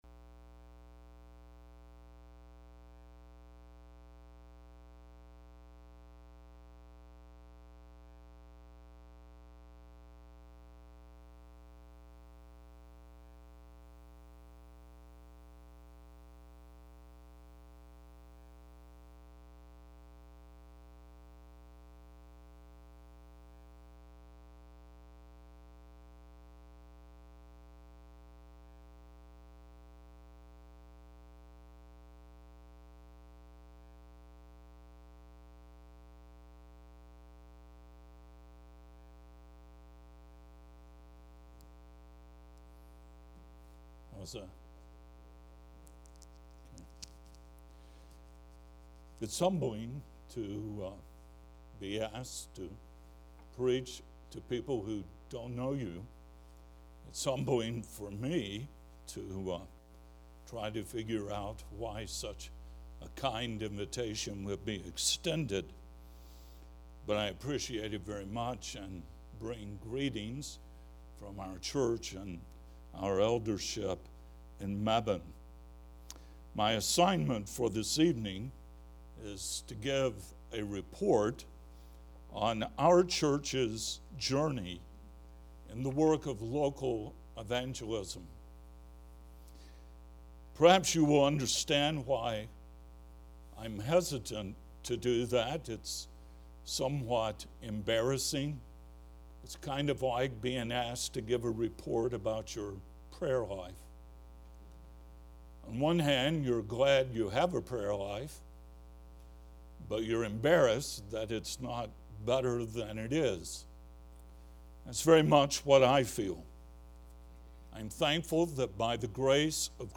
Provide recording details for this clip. FIRE 2015 Southeast Regional Conference